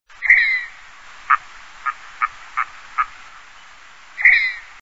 Harles huppés
Mergus serrator